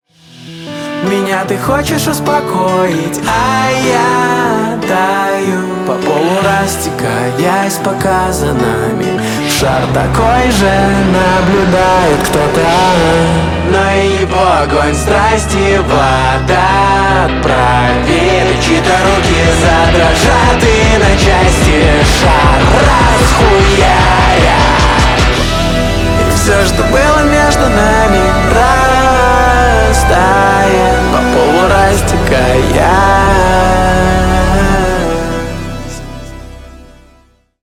Рок Металл